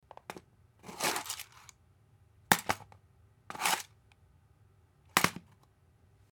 Звуки скрепок
Берем коробку в руку и кладем обратно